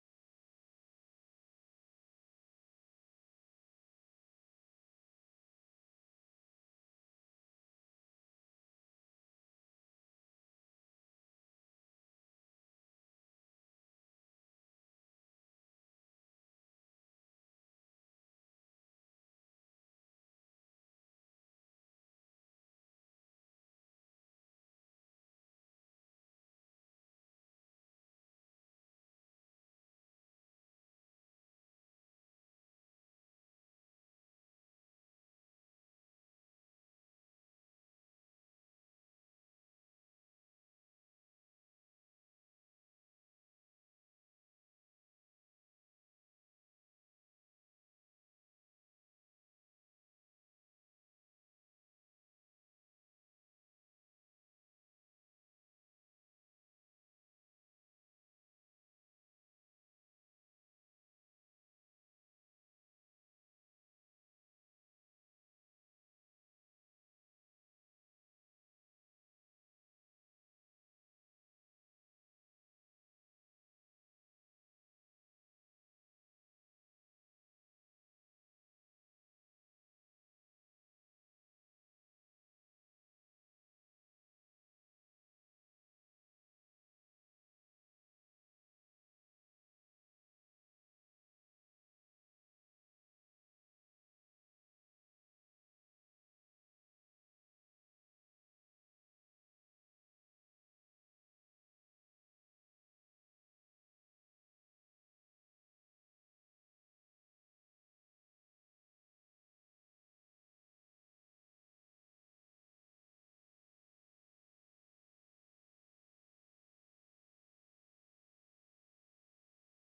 Our worship team leads us in some amazing worship.